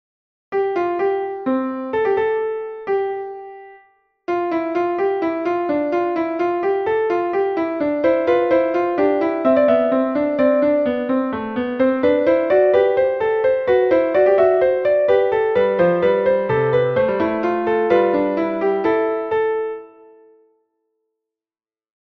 Partitura para piano dunha Fuga de Bach
bach_fuga.mp3